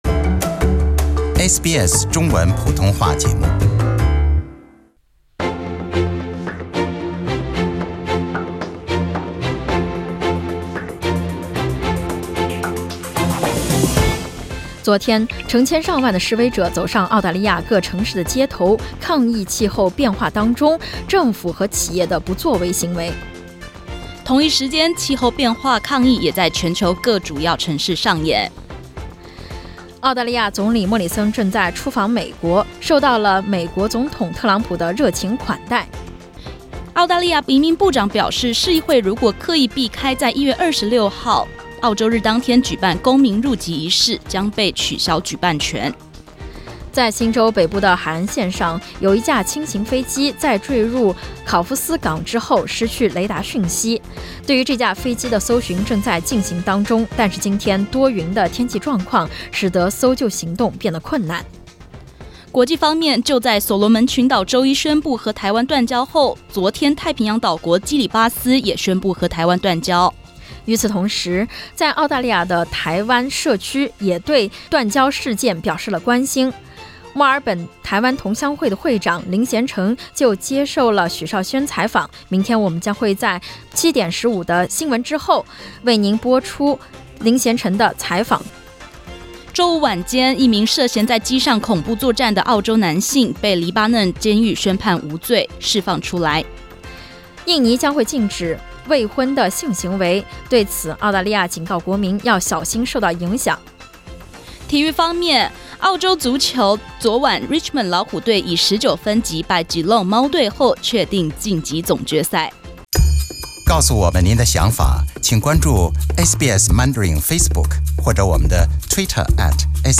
SBS早新闻（9月21日）